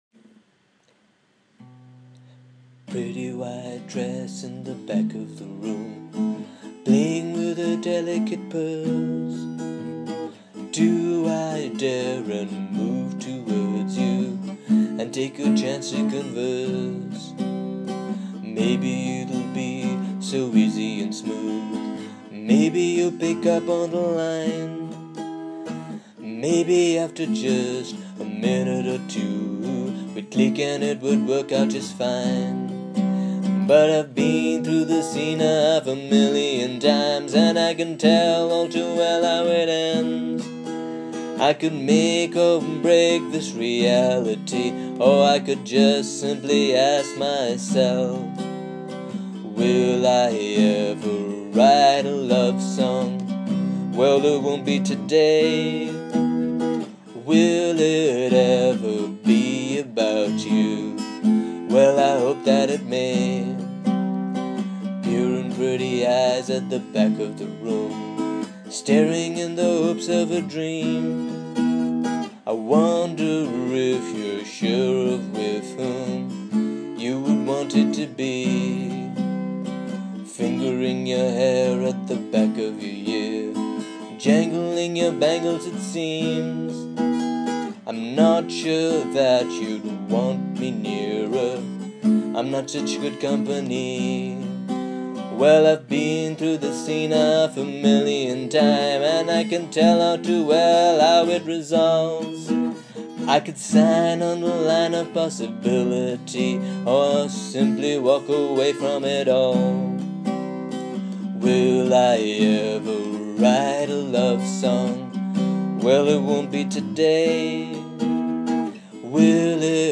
After several takes, and some tricky chord changes, I can finally put down this 'Will I Ever Write A Lovesong?'